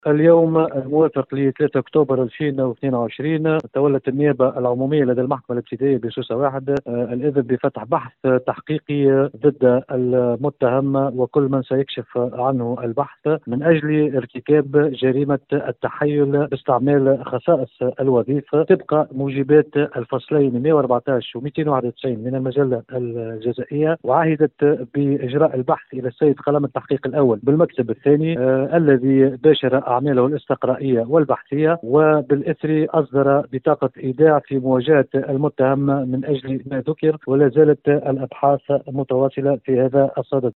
المساعد الأوّل لوكيل الجمهورية لدى المحكمة الابتدائية سوسة 1 والناطق الرسمي بها القاضي معز اليوسفي، في تصريح ل ام اف ام”. وأوضح اليوسفي، أنّ النيابة أذنت بفتح بحث تحقيقي لدى قاضي التحقيق، الذي باشر أعماله الاستقرائية الكاشفة للحقيقة، وقد توّلى إثر ذلك إصدار بطاقة إيداع بالسجن ضدّ المُتّهم، ومازالت الأبحاث متواصلة، حسب تعبيره.